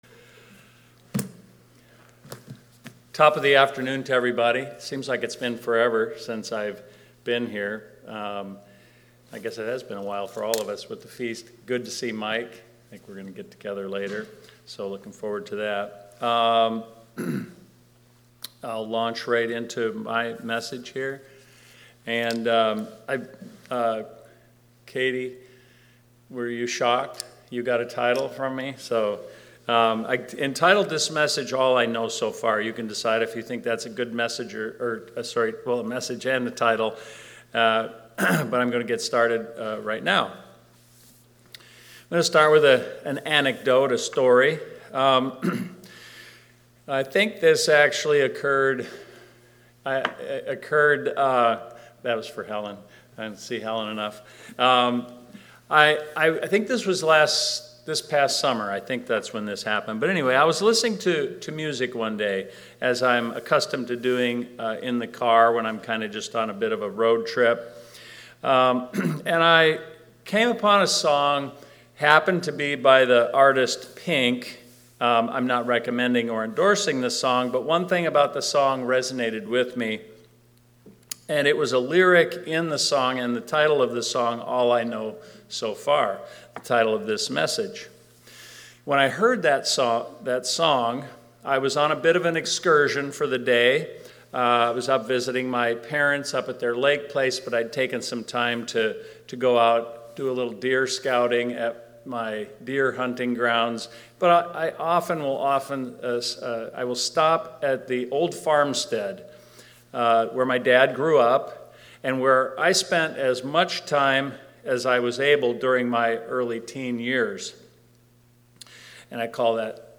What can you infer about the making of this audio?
Given in Twin Cities, MN